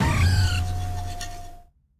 Cri de Flotte-Mèche dans Pokémon Écarlate et Violet.